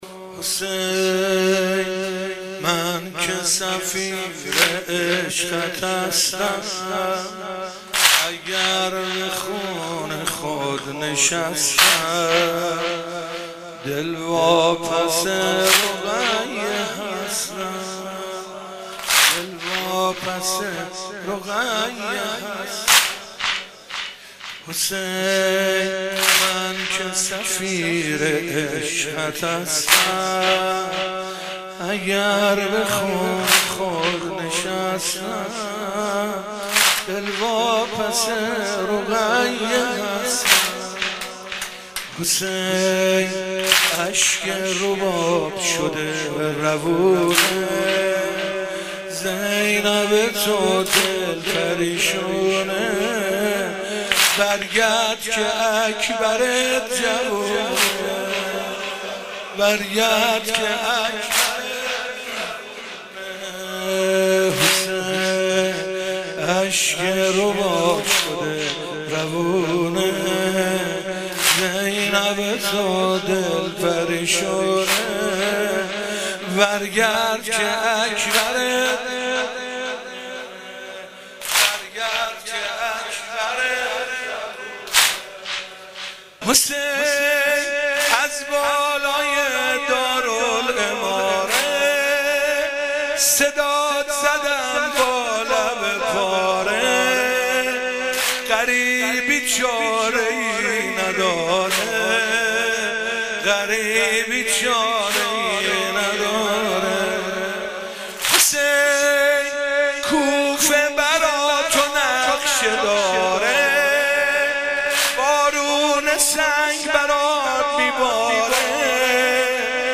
vahed shab 1.mp3